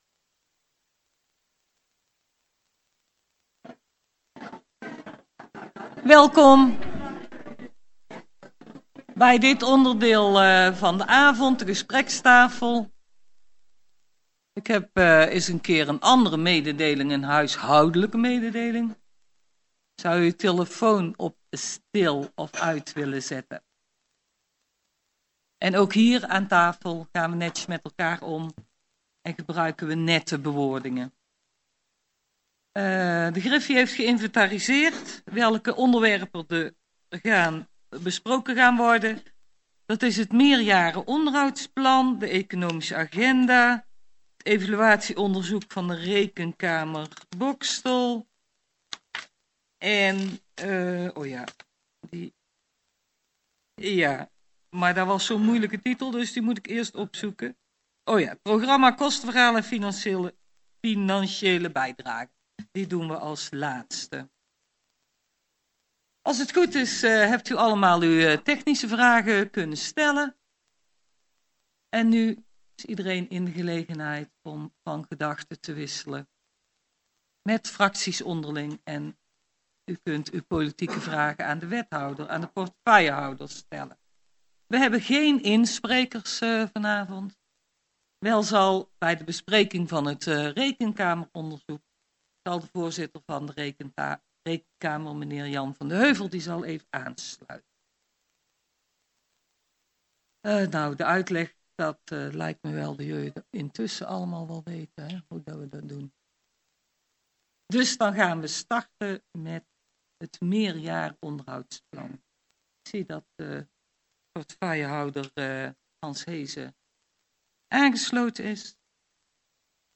Deze bijeenkomst vindt plaats in het gemeentehuis.